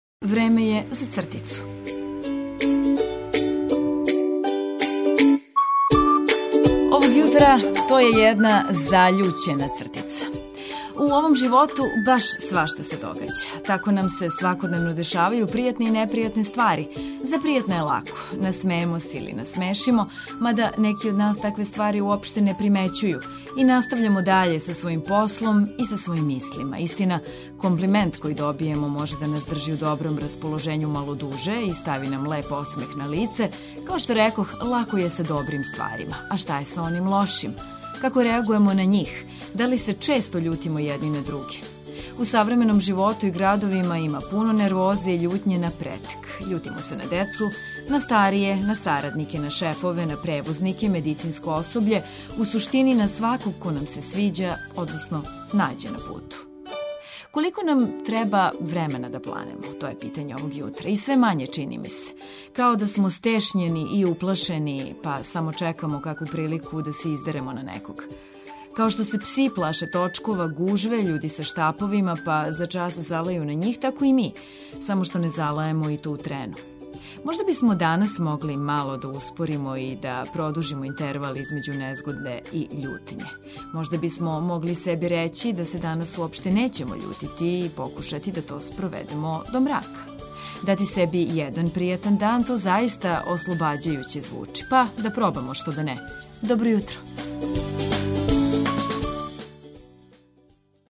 Обећавамо пријатно буђење уз летње хитове и важне вести.